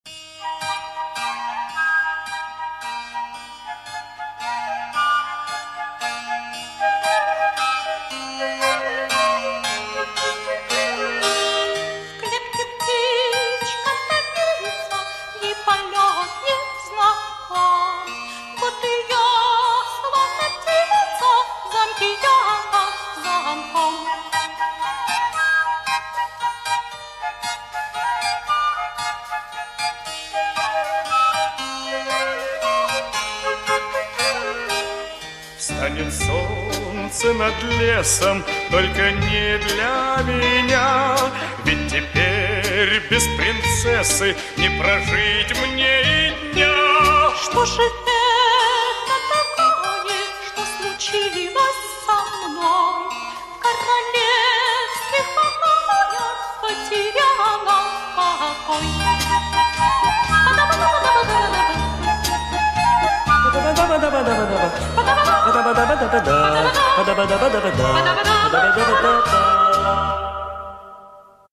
это серенада и легкая мелодия